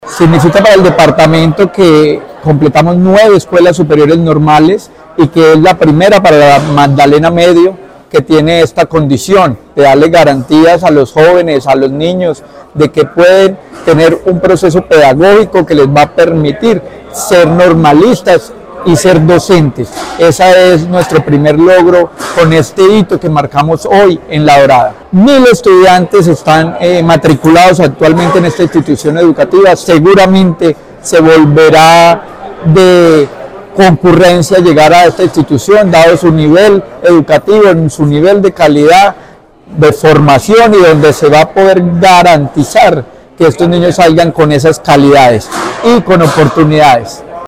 Luis Herney Vargas Barrera, secretario de Educación de Caldas
Secretario-de-Educacion-de-Caldas-Luis-Herney-Vargas-Barrera-Normal-Superior-La-Dorada.mp3